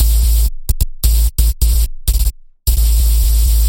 低音噪声
描述：低音噪音，用Zoom h2n记录。
标签： FX 噪声 击打 低音的 低音 下潜
声道立体声